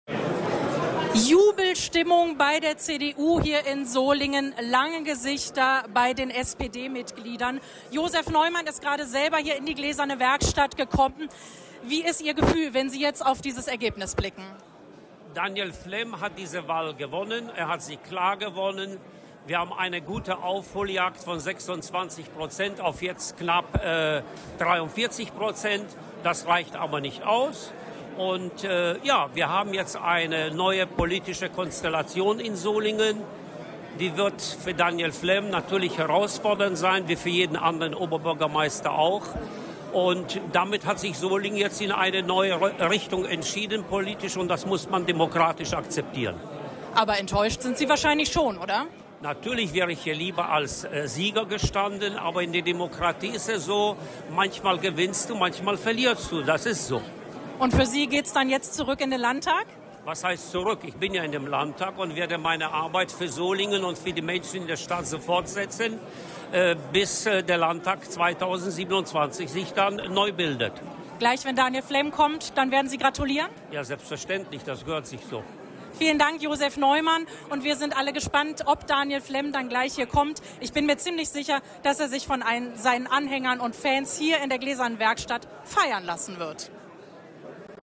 Die Stimmen der Gewinner und Verlierer